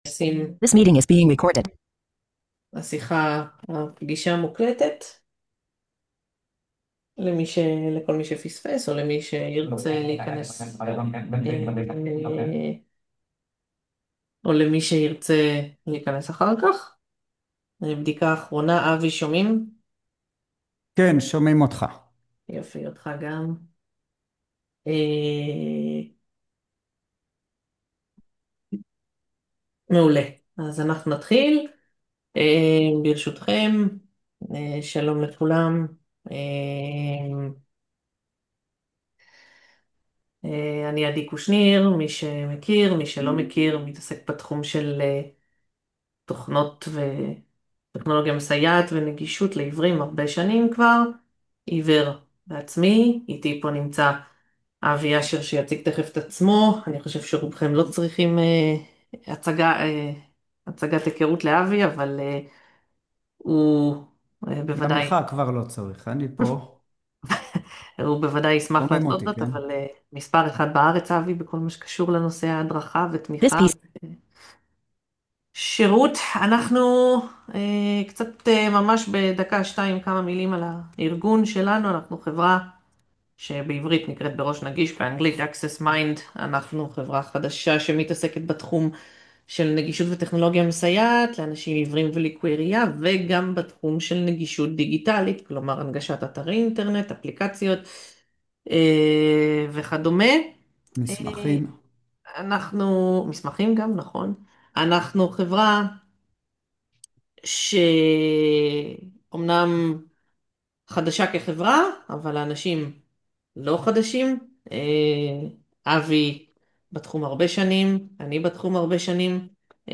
לקוחות ושותפים יקרים, ביום ב', 1.12.2025, התקיים מפגש השני שלנו ב-Zoom. במפגש הצגנו חידושים ועדכונים עבור Jaws / Fusion / zoomtext גרסה 2026.